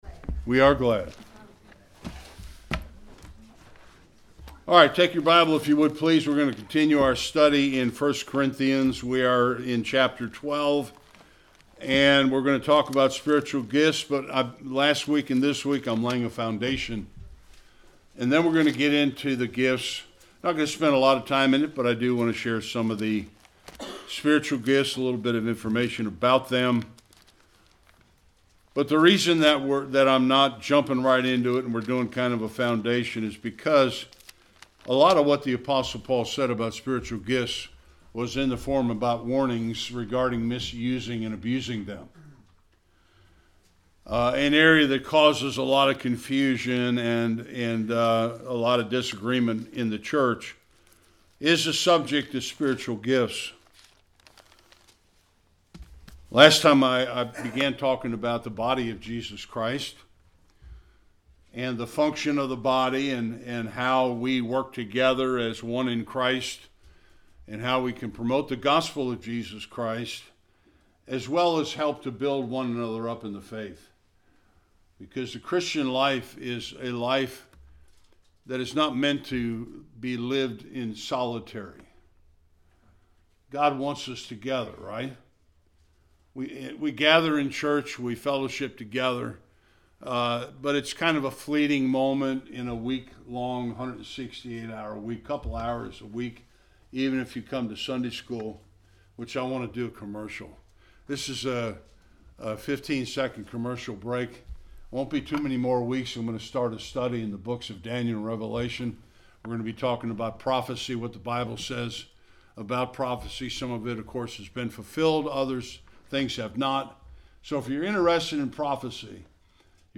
1 Corinthians 12 Service Type: Sunday Worship The Church